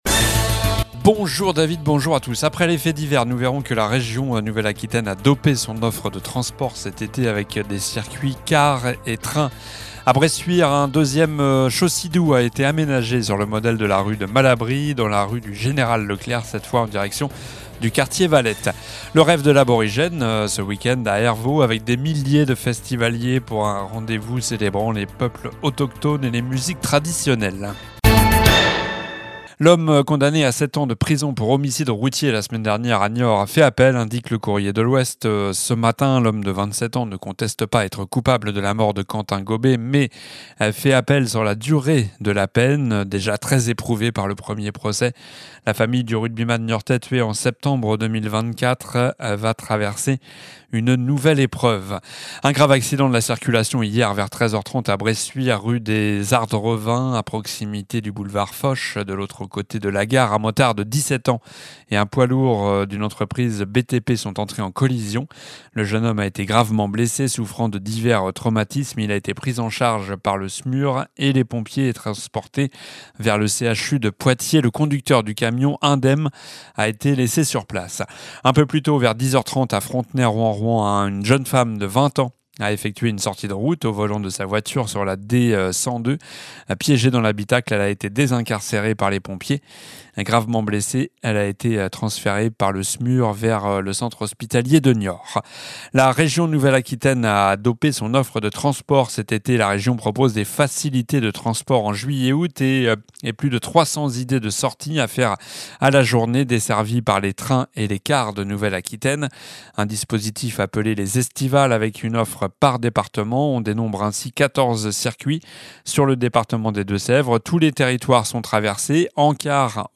Journal du jeudi 24 juillet (midi)